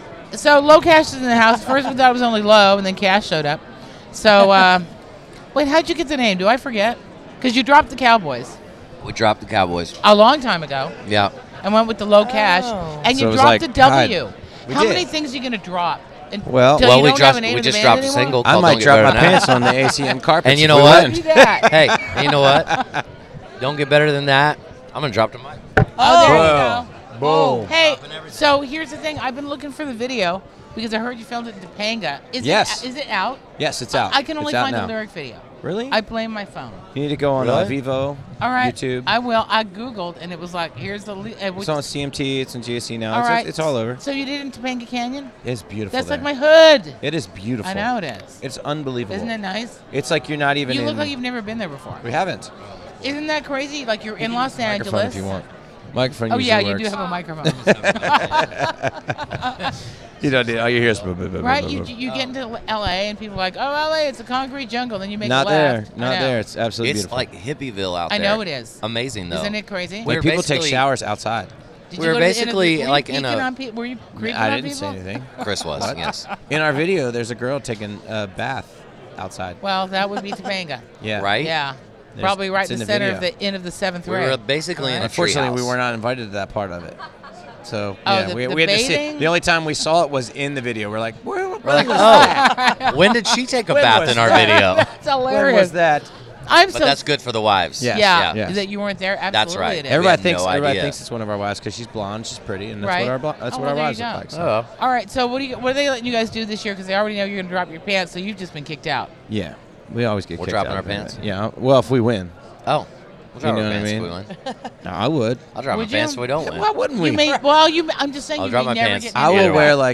LoCash Interview At 2018 ACMs!